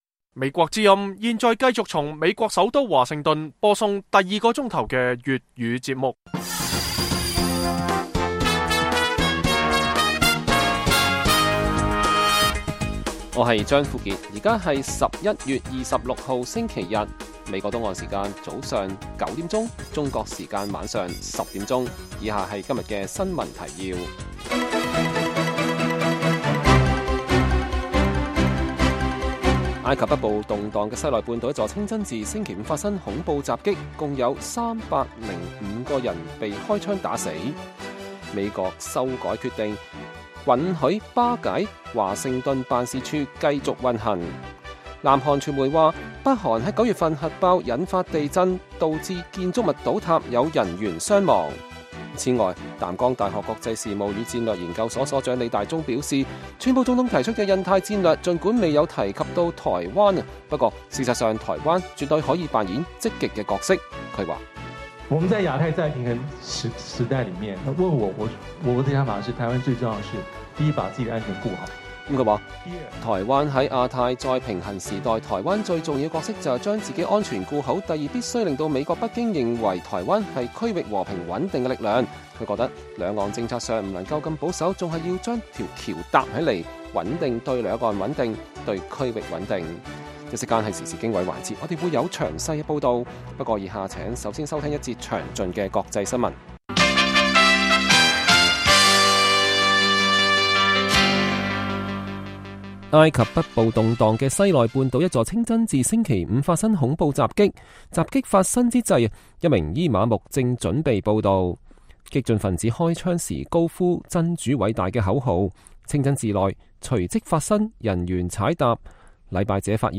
北京時間每晚10－11點 (1400-1500 UTC)粵語廣播節目。內容包括國際新聞、時事經緯和英語教學。